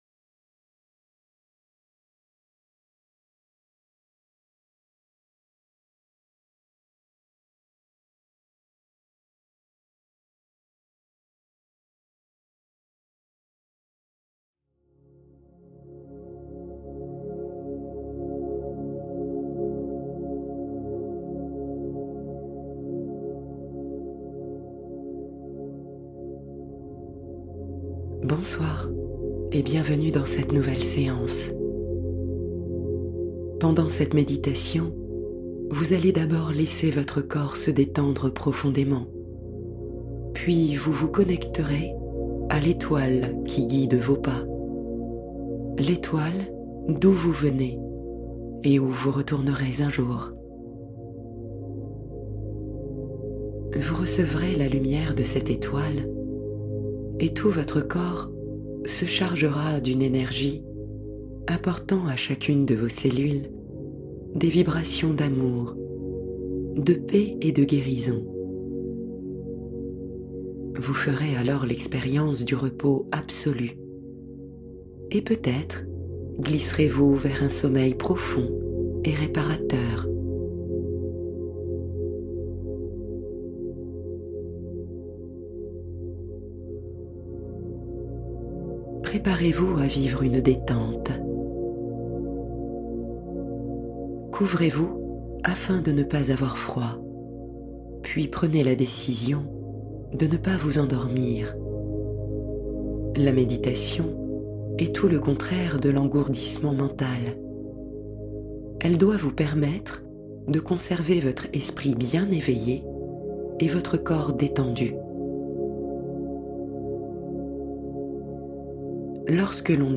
Guérir une partie de soi — Voyage guidé avec ho’oponopono